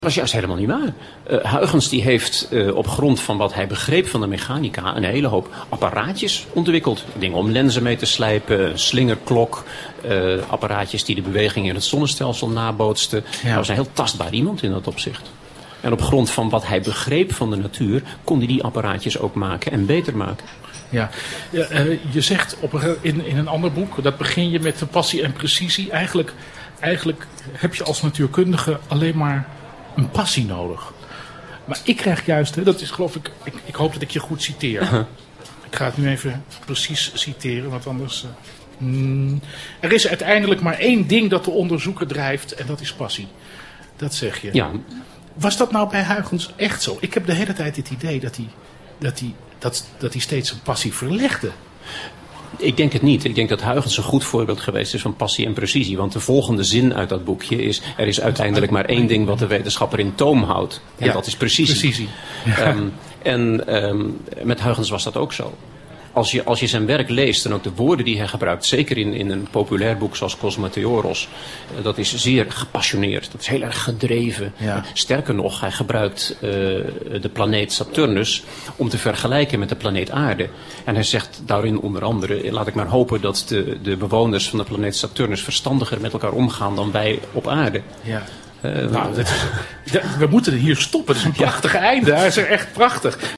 Prachtig enthousiasmerend interview met natuurkundige Vincent Icke in 747Live, over zijn held Christiaan Huygens.